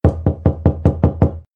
knock.mp3